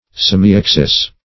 Search Result for " semiaxis" : The Collaborative International Dictionary of English v.0.48: Semiaxis \Sem`i*ax"is\, n. (Geom.) One half of the axis of an ?llipse or other figure.